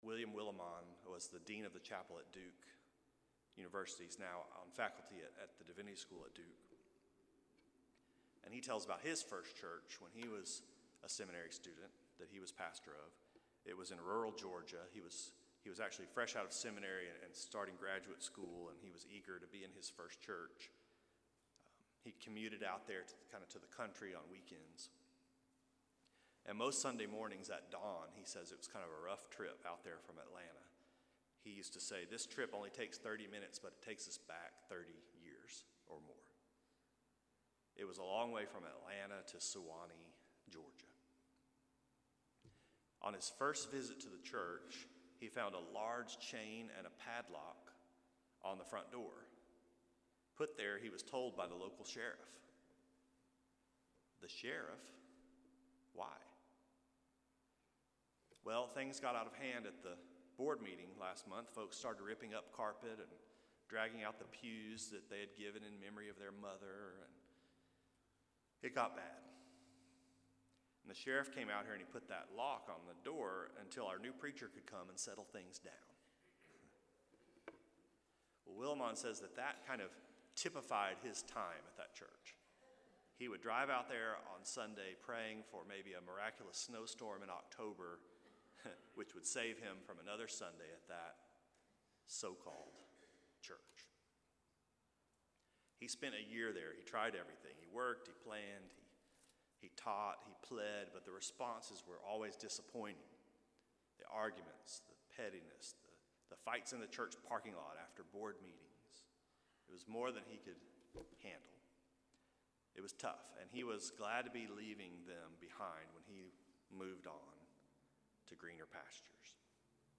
Here is the audio and full transcript of last Sunday’s sermon. The audio is in two parts, and it starts with the children’s sermon and anthem.
Second Sunday of Easter